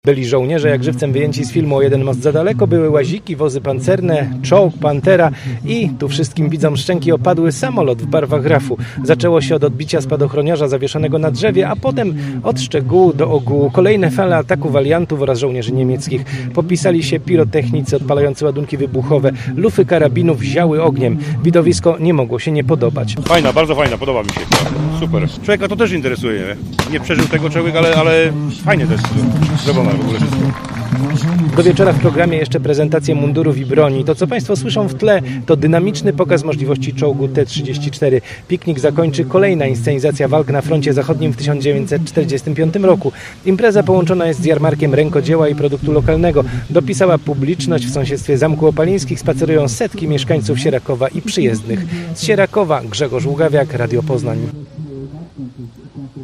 495p0y9dejaxmgr_piknik_historyczny_paralive.mp3